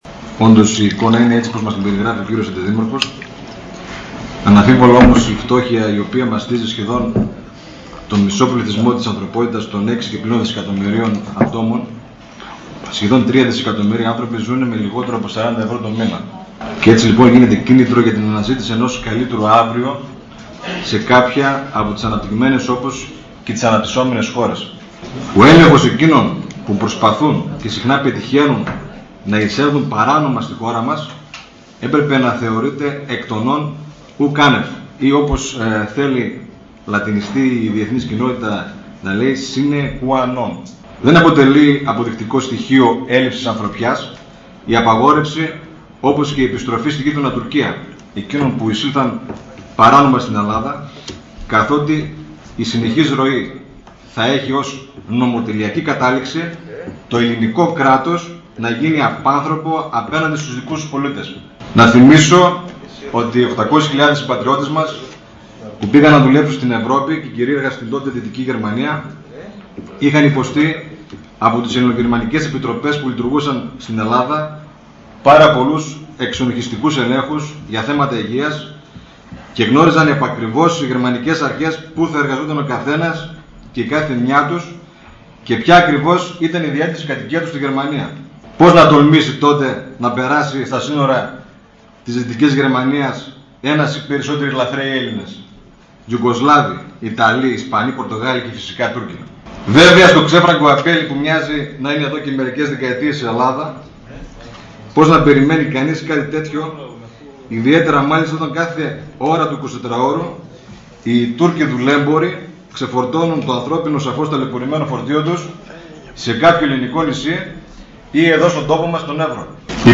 Γιώργος Καραμανίδης, δημ. συμβ. για το θέμα της λαθρομετανάστευσης – Δημ. Συμβ. Ορ/δας 14.09.2010